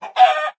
sounds / mob / chicken / hurt1.ogg
hurt1.ogg